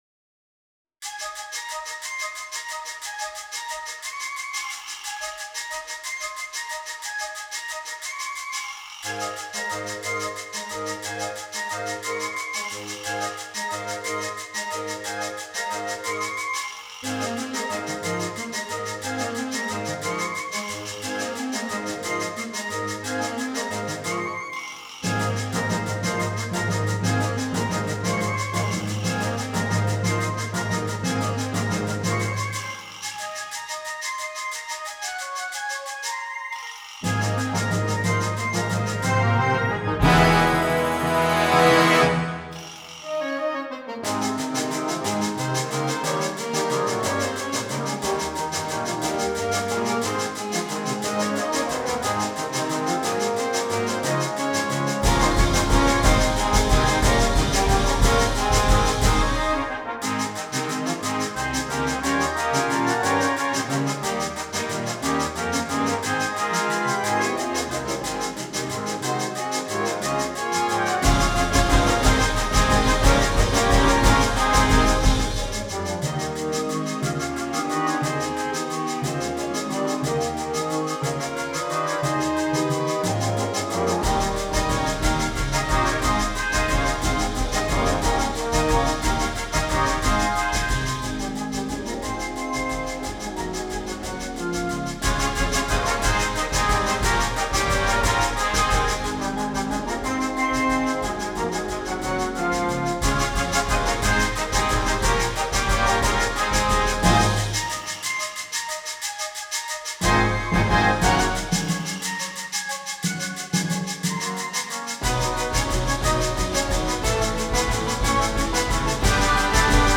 • Piccolo
• Flauta
• Oboe
• Fagot
• Clarinete Bajo
• Saxofón Tenor
• Saxofón Barítono
• Tuba
• Güiro